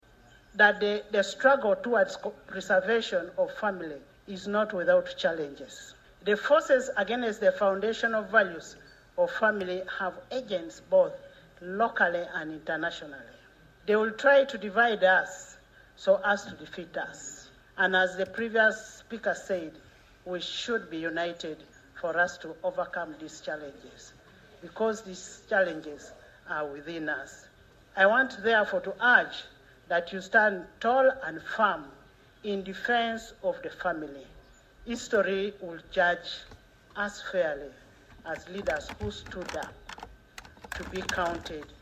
Top political leaders, parliamentarians and faith-based actors from across Africa and beyond have this week convened at State House, Entebbe for the Third African Inter-Parliamentary Conference on Family and Sovereignty, issuing a strong call for an African Charter that defends traditional values, strengthens national sovereignty and resists external ideological pressure.
AUDIO: Speaker Anita Among